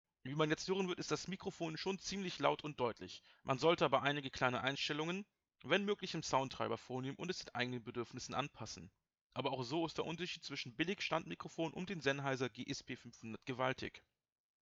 Die Qualität der Stimme ist durchaus gegeben. Deutlich, laut und verständlich.
Hier ein Sample: (Und nein, meine Stimme ist nicht schön basslastig.
Neue-Testaufnahme-GSP-500-online-audio-converter.com_.mp3